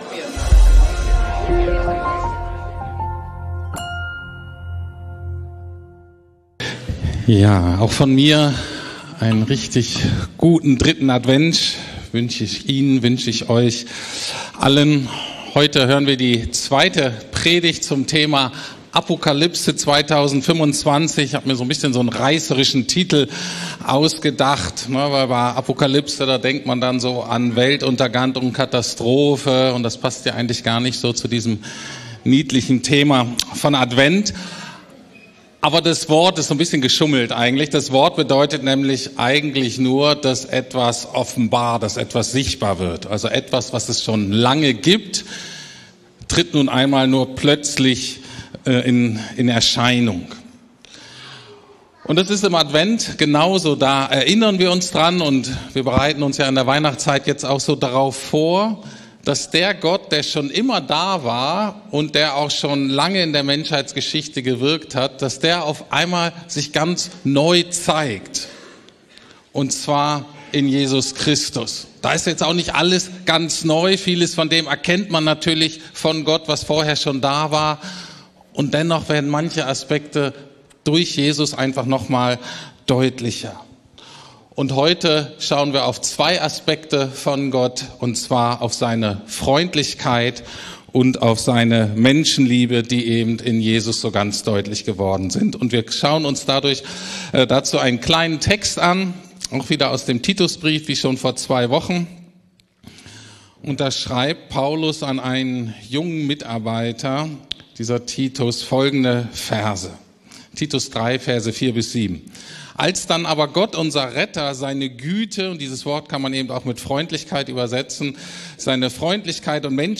Apokalypse 2025 Gott erscheint in Freundlichkeit ~ Predigten der LUKAS GEMEINDE Podcast